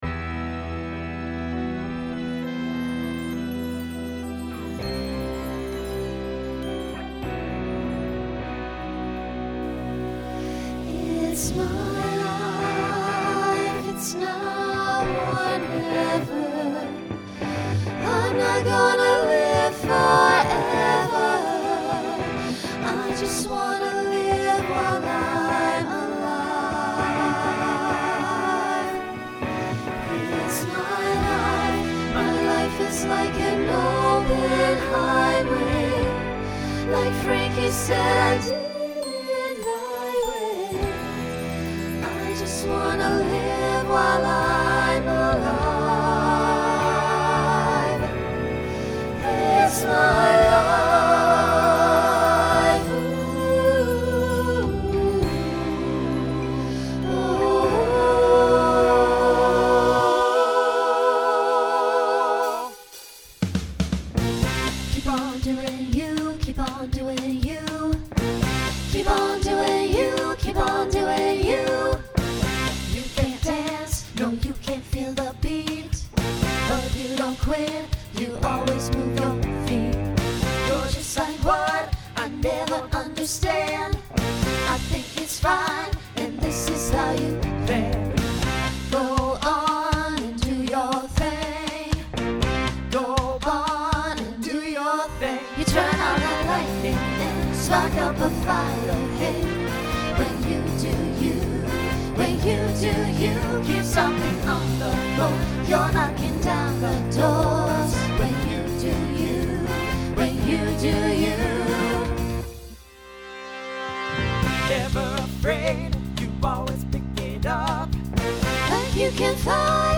Rock Instrumental combo
Voicing SATB